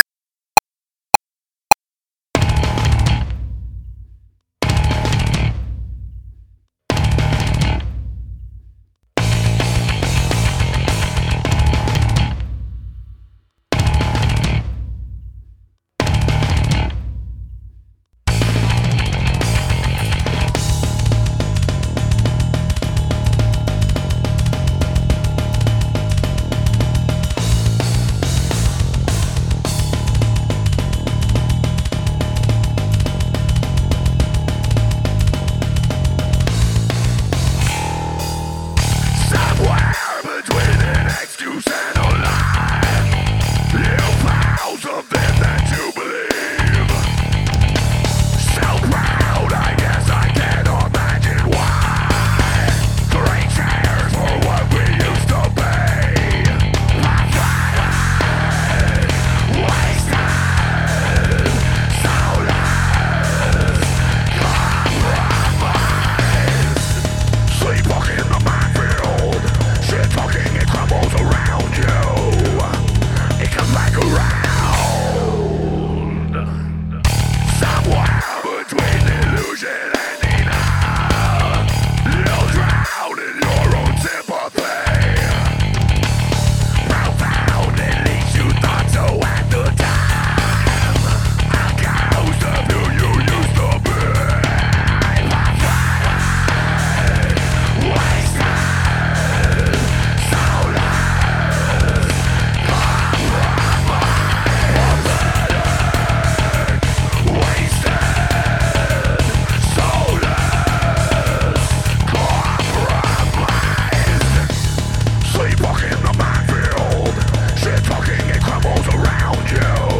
Tempat Download Backing Track